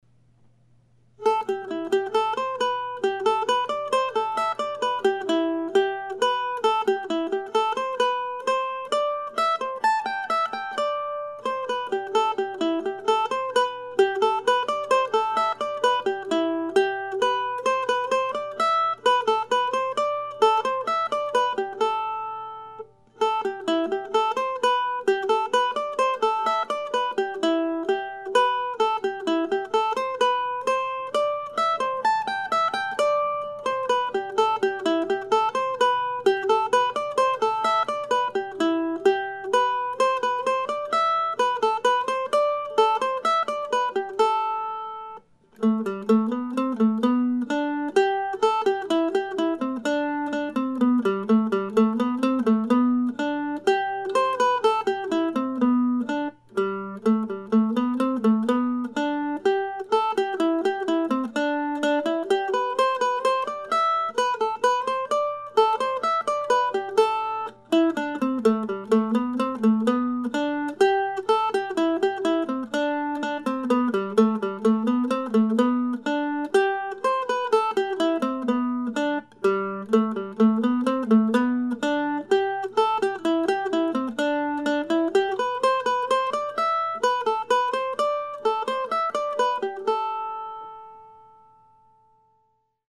Early in 2016 I started writing short pieces modeled after the Divertimentos that James Oswald composed and published in the 1750s in London.
I've been playing them before or after Oswald's own pieces during my solo mandolin coffee house gigs this year and now my plan is to turn them into a small book that I intend to have available at the Classical Mandolin Society of America annual convention in Valley Forge, early next month.